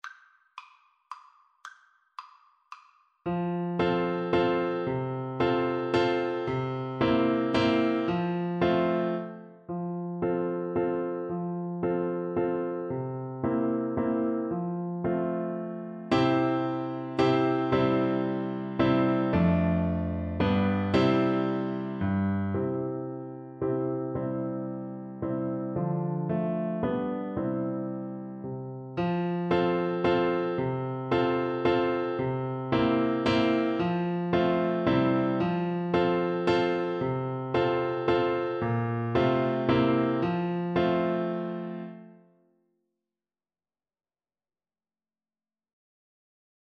Play (or use space bar on your keyboard) Pause Music Playalong - Piano Accompaniment Playalong Band Accompaniment not yet available transpose reset tempo print settings full screen
French HornPiano
Allegro = c. 112 (View more music marked Allegro)
3/4 (View more 3/4 Music)
F major (Sounding Pitch) C major (French Horn in F) (View more F major Music for French Horn )
French Horn  (View more Beginners French Horn Music)
Traditional (View more Traditional French Horn Music)